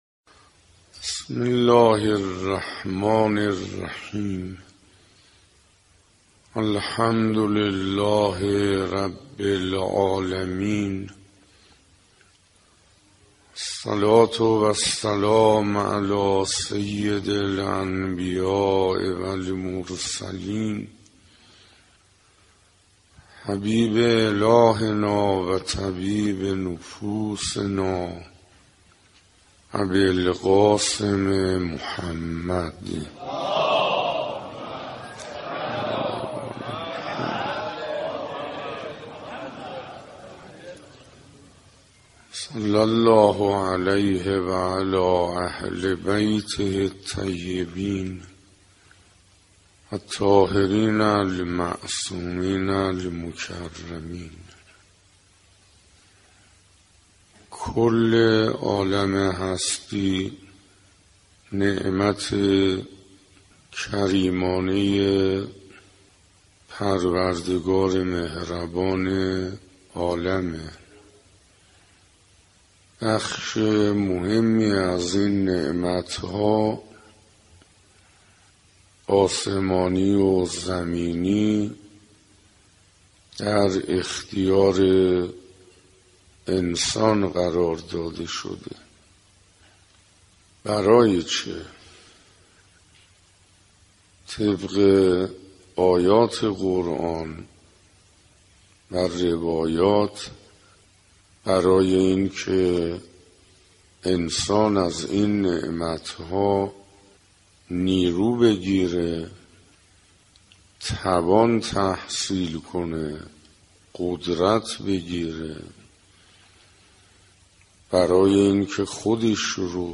دانلود چهاردهمین جلسه از بیانات آیت الله حسین انصاریان با عنوان «ارزشهای ماه رمضان»